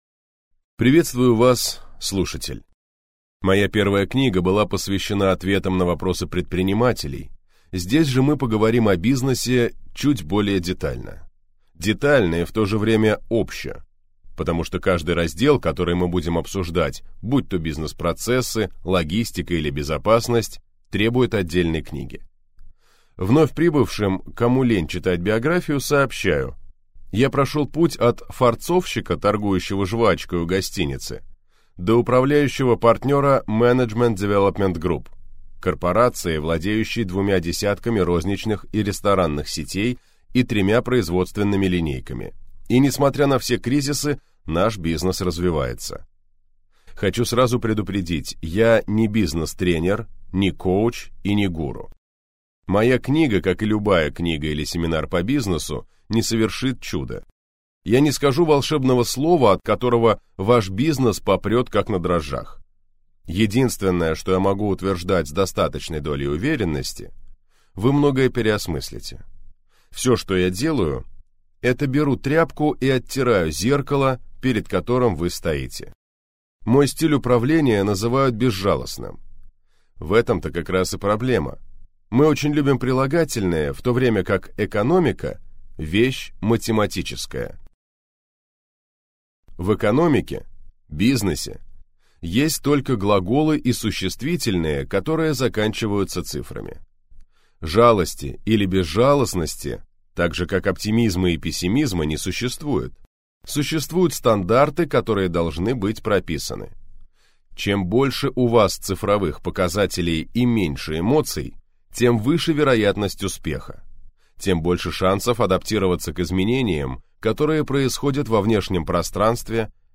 Аудиокнига Бизнес – это глаголы и существительные, которые заканчиваются цифрами | Библиотека аудиокниг